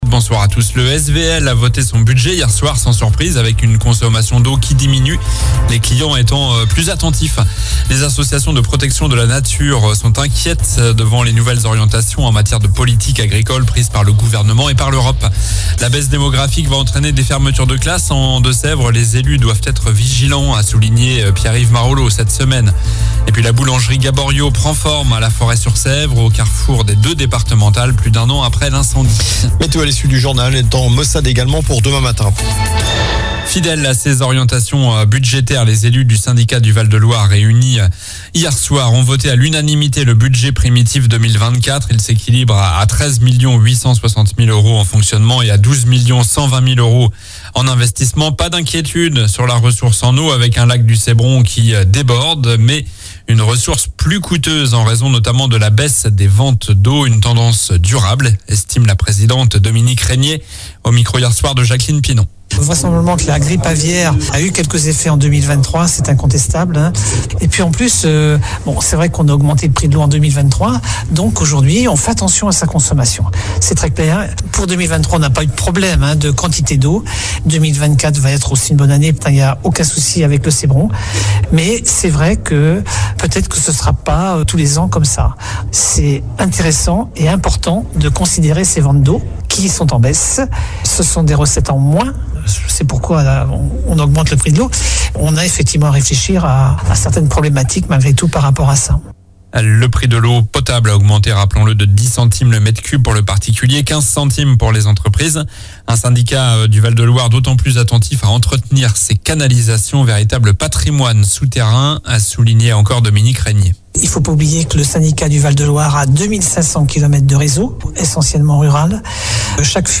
Journal du jeudi 08 février (soir)